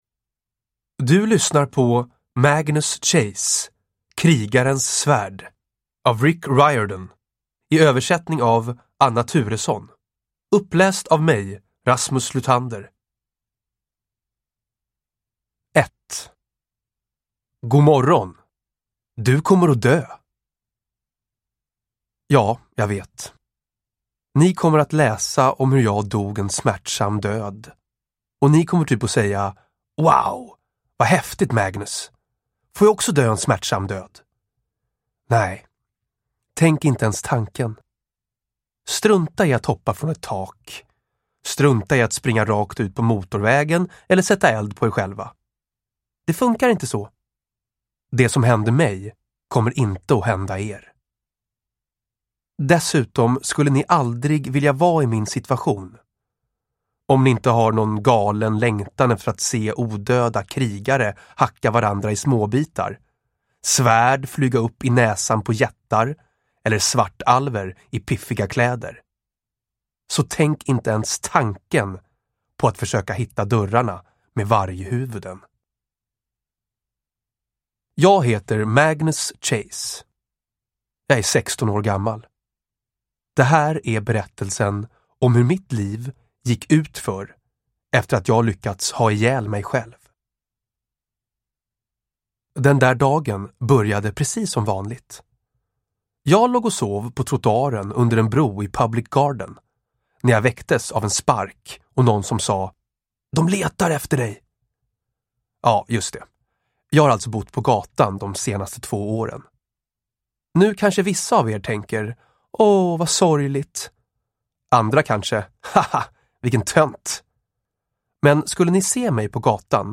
Krigarens svärd – Ljudbok